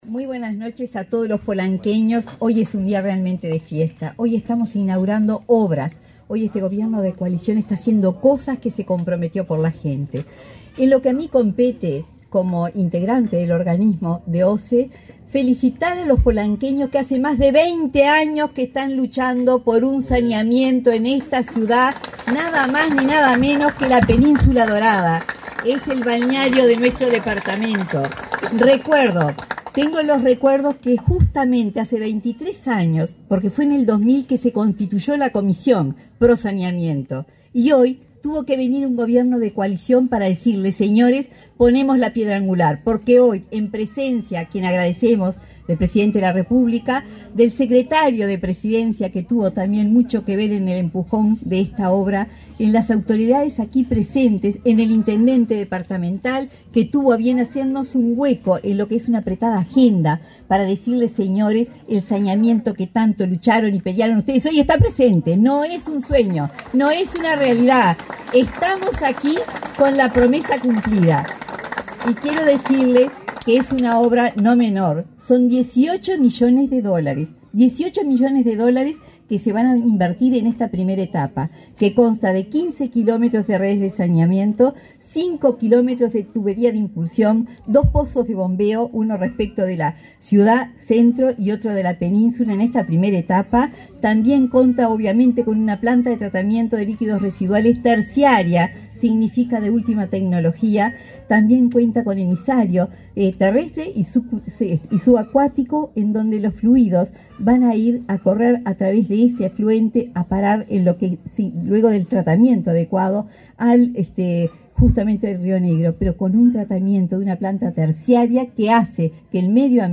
Palabras de la vicepresidenta de OSE, Susana Montaner
Palabras de la vicepresidenta de OSE, Susana Montaner 31/01/2023 Compartir Facebook X Copiar enlace WhatsApp LinkedIn Este 30 de enero, durante su visita a Tacuarembó, el presidente Luis Lacalle Pou concurrió al inicio de las obras de saneamiento en San Gregorio de Polanco, proyecto que otorgará el servicio al 65% de la ciudad e insumirá una inversión de 13 millones de dólares. La vicepresidenta de OSE, Susana Montaner, brindó información sobre el tema.